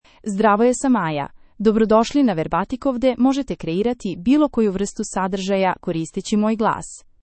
FemaleSerbian (Serbia)
Maya — Female Serbian AI voice
Maya is a female AI voice for Serbian (Serbia).
Voice sample
Listen to Maya's female Serbian voice.